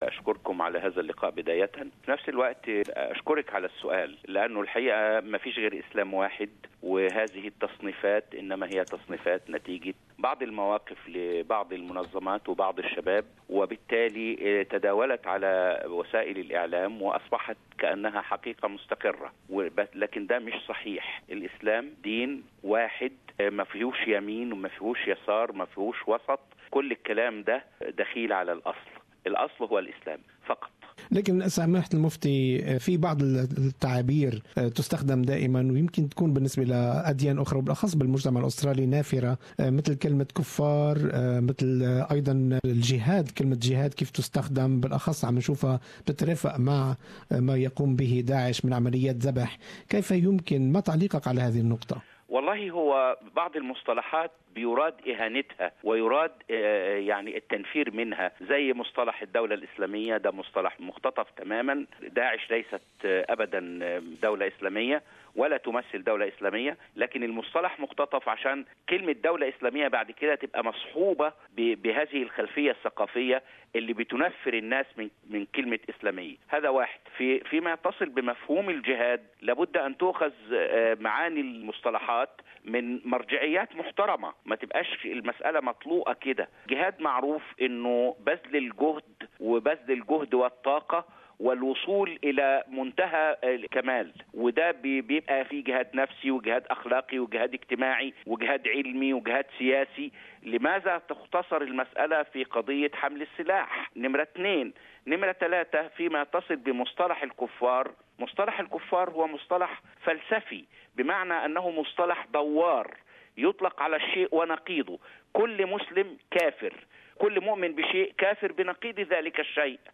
Listen to Mufti Dr. Ibrahim Abou Mohammed talking